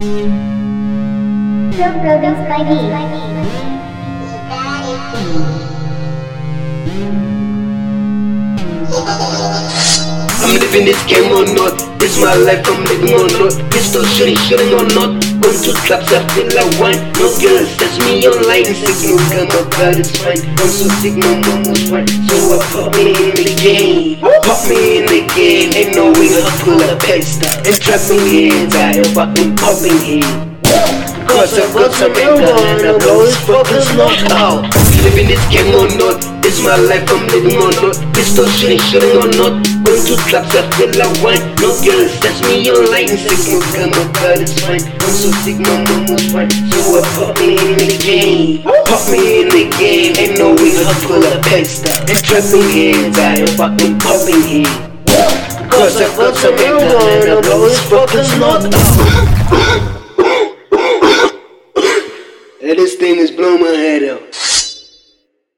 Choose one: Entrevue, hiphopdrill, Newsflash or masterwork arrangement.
hiphopdrill